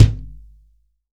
TUBEKICKT2-S.WAV